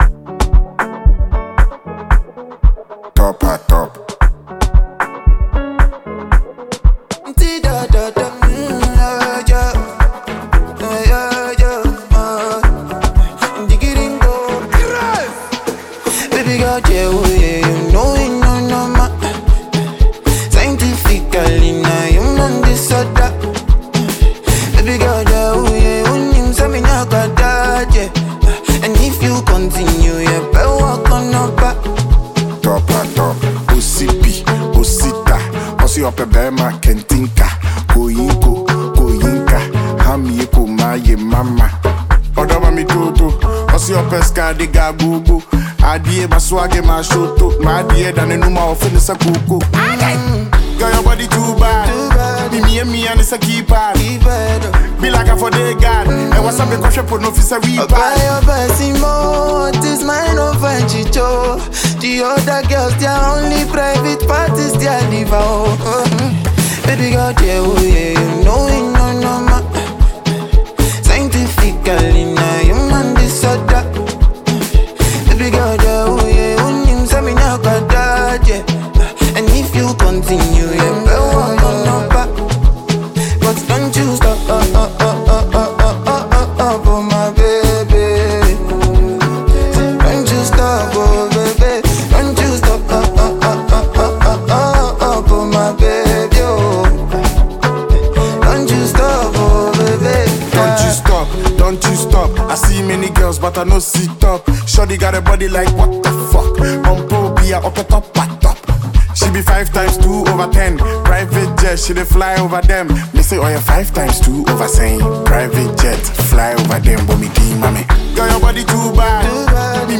Enjoy a new tune from Ghanaian rapper